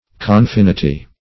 Search Result for " confinity" : The Collaborative International Dictionary of English v.0.48: Confinity \Con*fin"i*ty\, n. [Cf. F. confinit['e].]